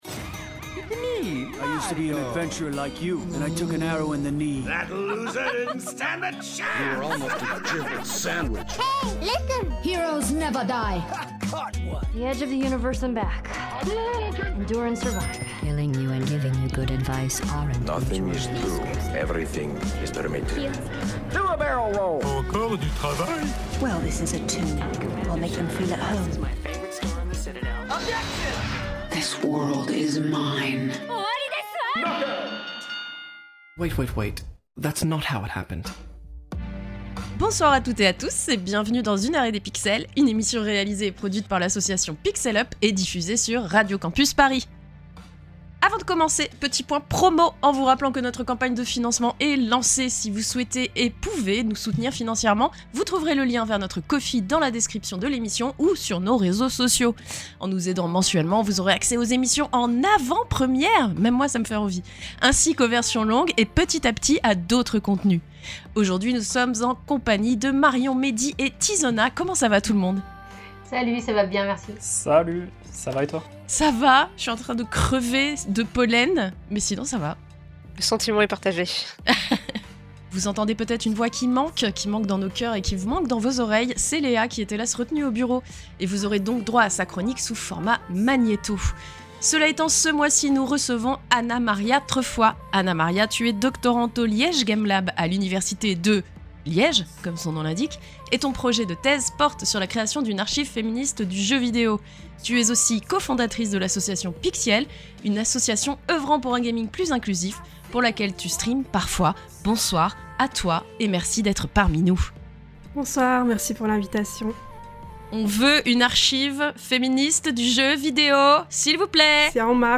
Émission diffusée le 19 avril 2025 sur Radio Campus Paris.
Magazine Culture